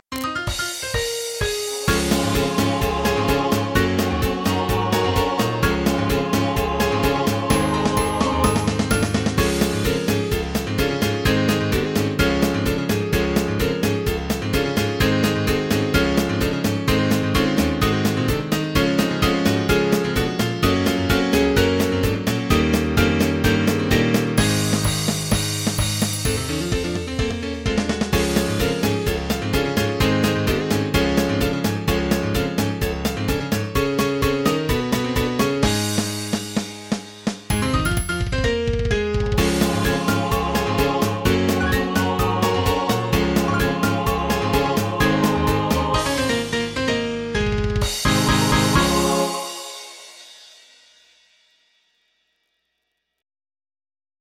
MIDI 21.31 KB MP3 (Converted)